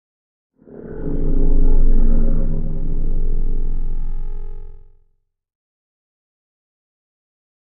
Digital Digital Feedback with Reverb